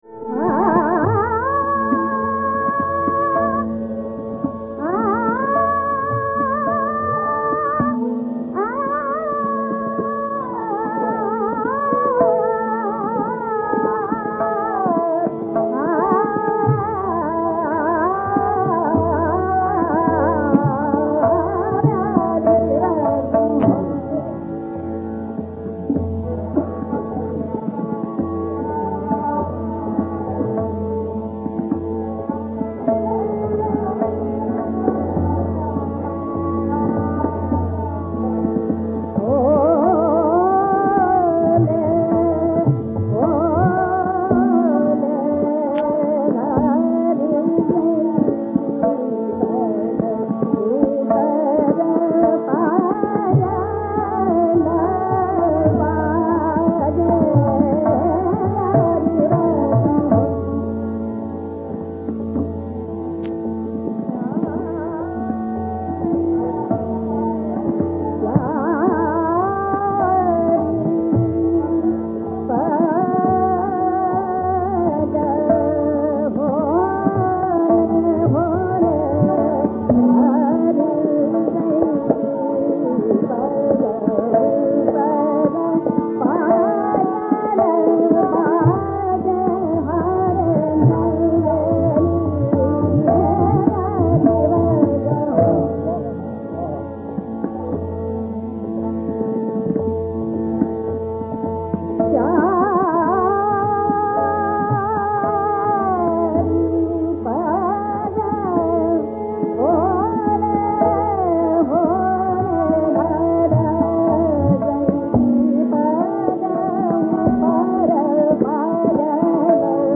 Raga Bihagda
Your attention is drawn to the explicitly intoned teevra madhyam in this rendition.
The phraseology supporting teevra madhyam advanced by Mogubai creates an avirbhava of Raga Nand.
mogubai_bihagda_vil.mp3